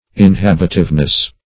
Search Result for " inhabitiveness" : The Collaborative International Dictionary of English v.0.48: Inhabitiveness \In*hab"it*ive*ness\, n. (Phrenol.)